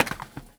R - Foley 143.wav